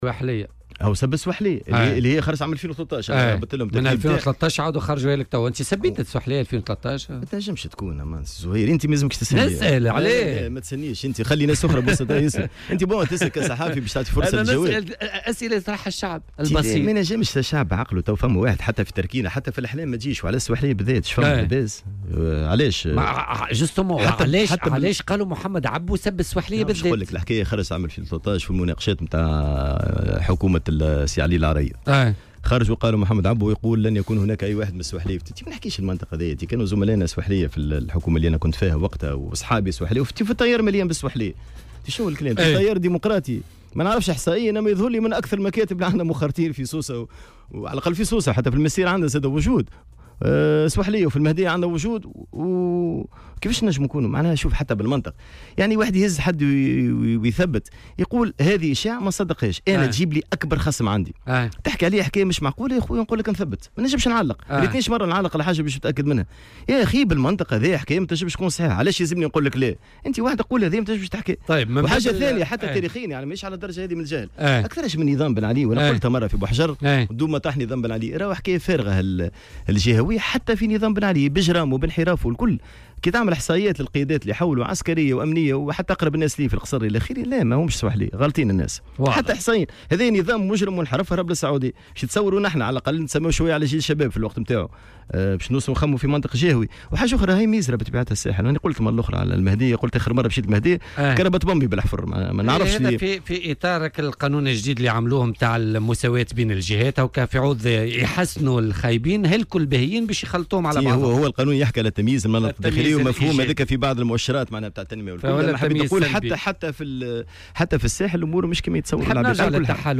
واستنكر ضيف بوليتيكا" على "الجوهرة أف أم" ترويج مثل هذه الإشاعات التي لا يقبلها أي انسان عاقل، وفق تعبيره، مؤكدا أن حزبه يضم عددا كبيرا من "السّواحلية" في واقع الأمر.